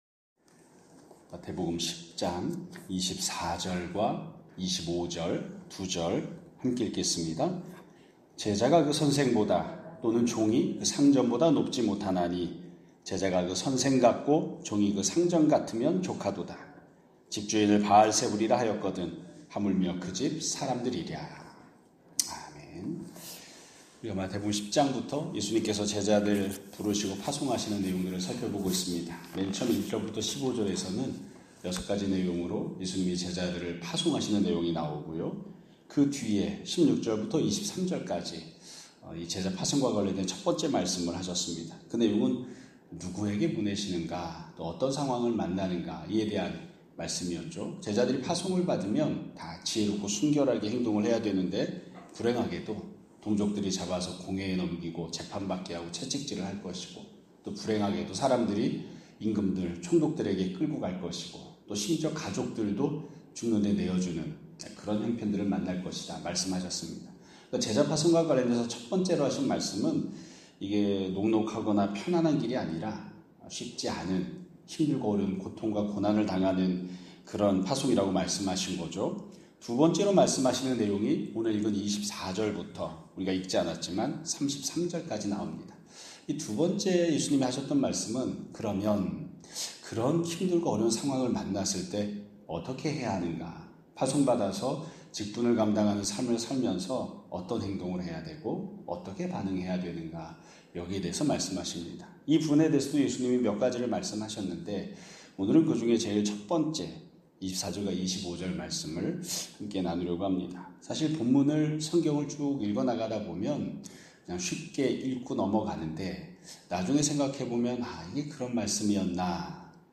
2025년 8월 11일 (월요일) <아침예배> 설교입니다.